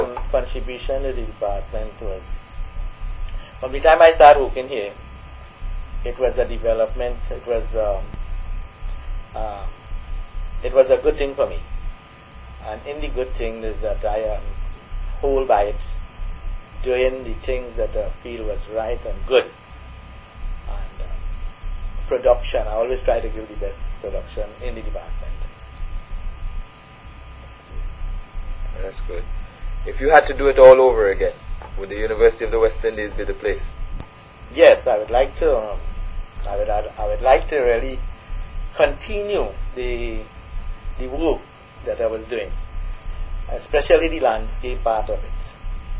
l audio cassette